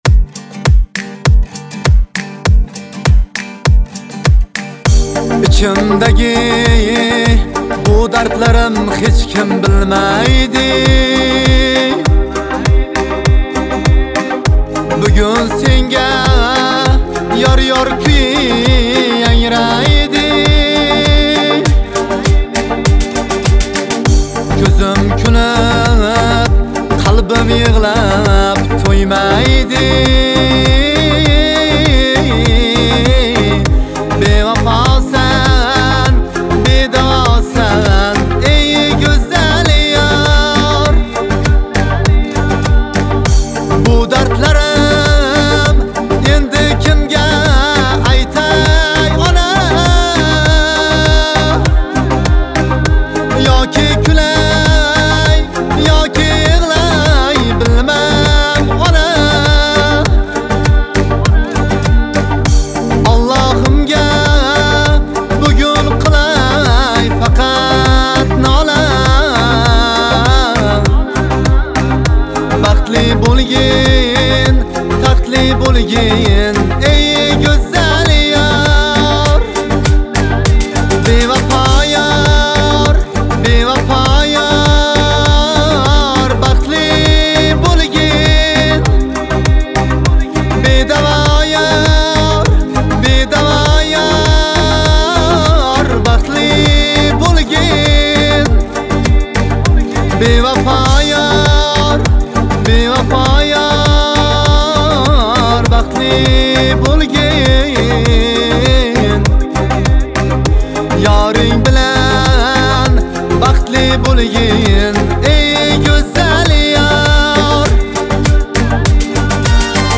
Жанр: Ошабам кушиклари